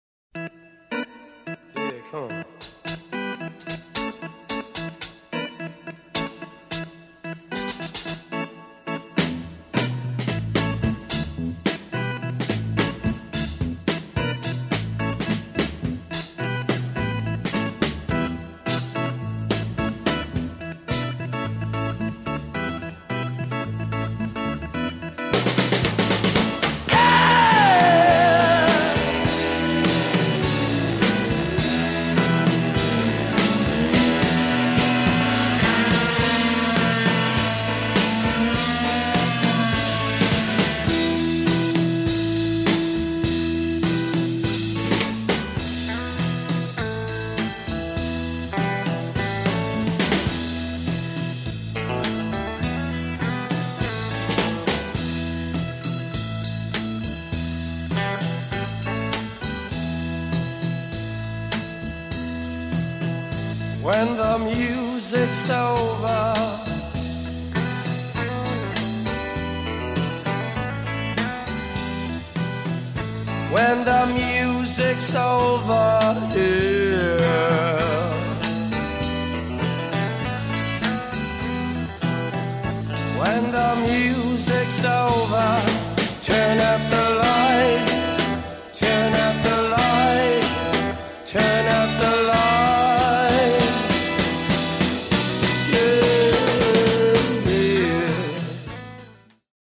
I used a Vox Continental on the first two albums